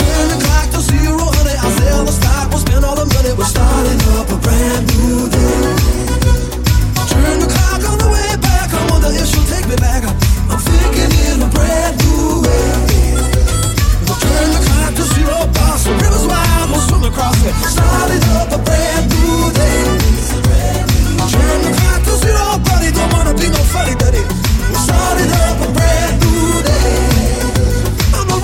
Genere: pop, latin pop, remix